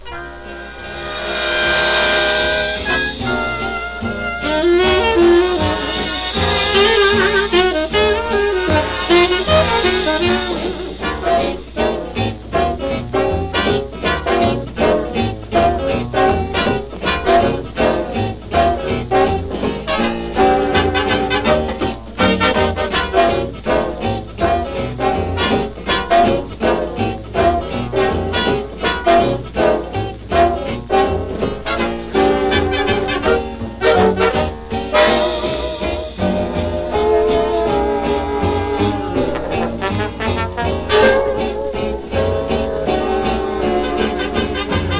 Jazz, Big Band　UK　12inchレコード　33rpm　Mono